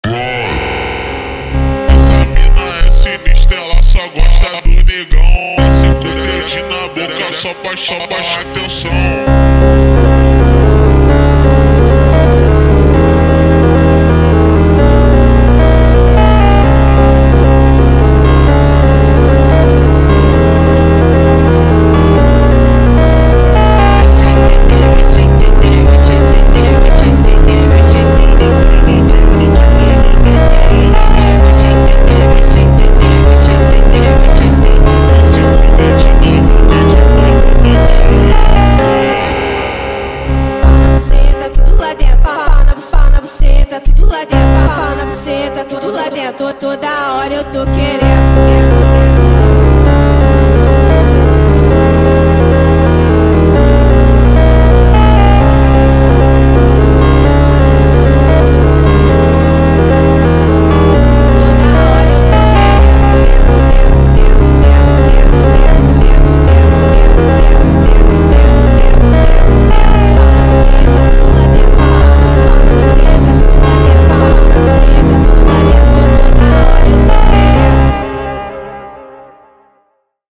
فانک جدید و مخصوص ادیت
فانک